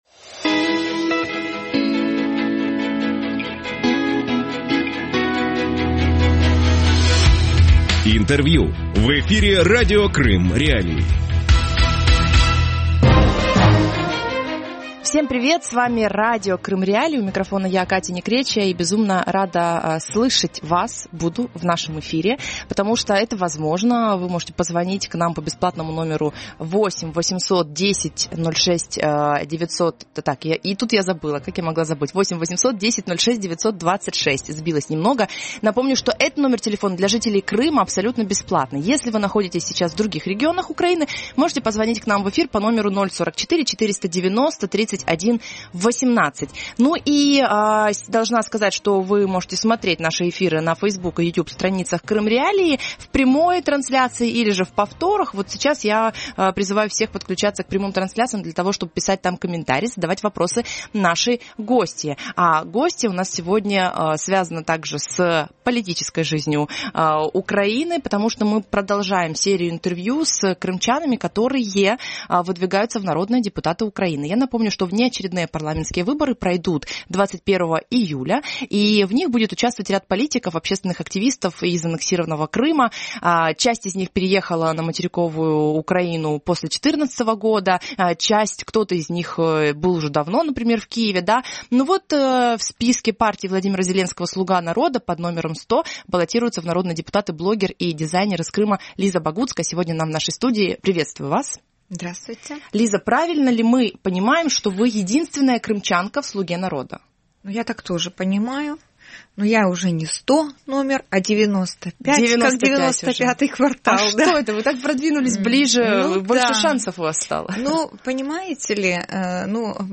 Гость эфира